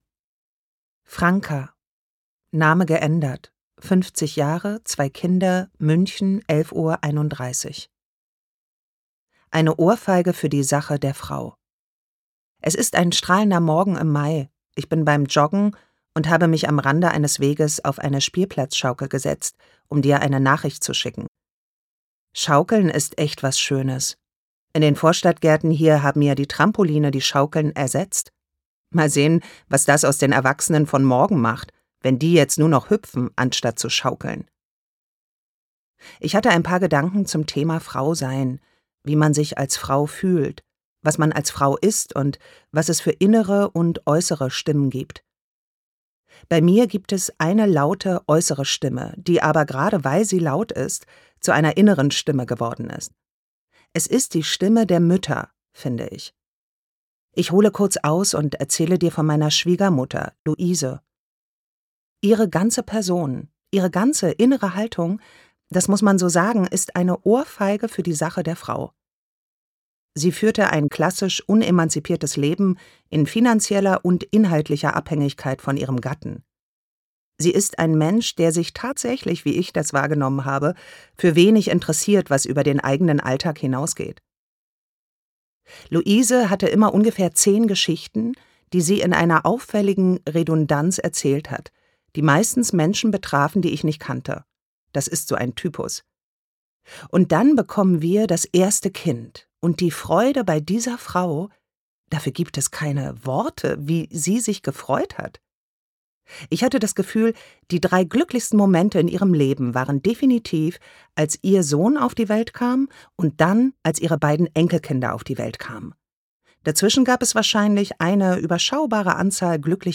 33 Sprachnachrichten von Frauen, die in der Mitte des Lebens stehen, in denen sie offen, intim und halb anonym über all das sprechen, was man mal abfällig Frauenprobleme nannte. Ein Chor weiblicher Stimmen, der unbeschönigt zeigt, wo eine ganze Generation steht.
Gekürzt Autorisierte, d.h. von Autor:innen und / oder Verlagen freigegebene, bearbeitete Fassung.